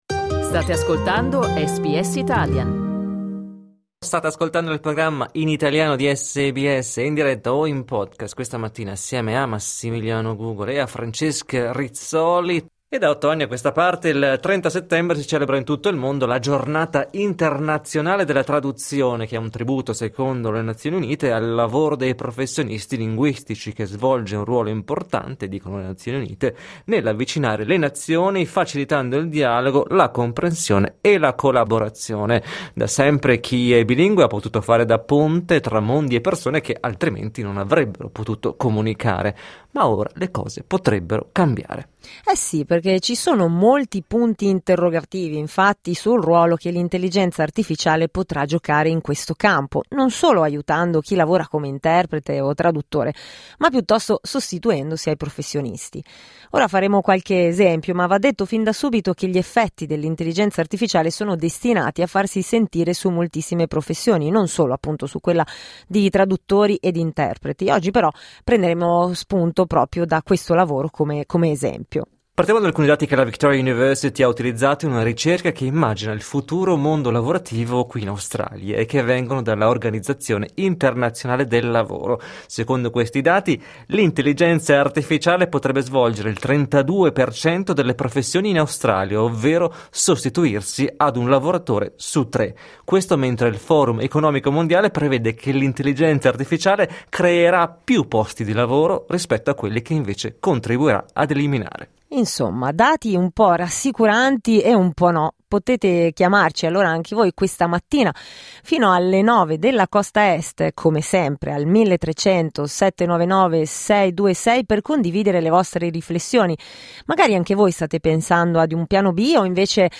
L’intelligenza artificiale potrebbe svolgere il 32% degli impieghi in Australia, ovvero sostituirsi ad un lavoratore su tre. In questo podcast chiediamo a traduttori ed interpreti come vedono il loro futuro, con sfide comuni a molte altre professioni.